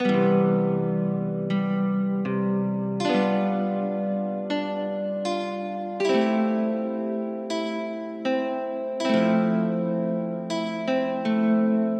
原声吉他循环播放
描述：我在fl studio上做的一个八小节原声吉他循环。
标签： 160 bpm Acoustic Loops Guitar Acoustic Loops 2.02 MB wav Key : C FL Studio
声道立体声